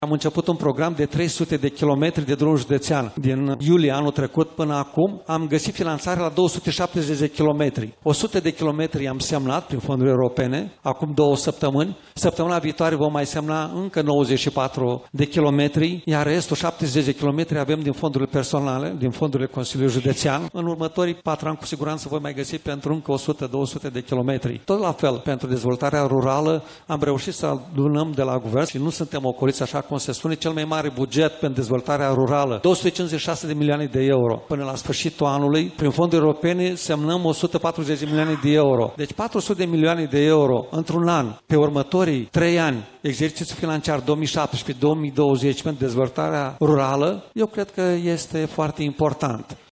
La eveniment a participat şi preşedintele Consiliului Judeţean Iaşi, Maricel Popa, care a punctat importanţa dezvoltării rurale, în special a infrastructurii, pentru deschiderea produselor agricole către noi pieţe de desfacere: